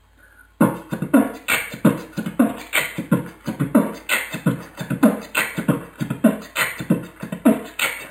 pf t t b pf t keh t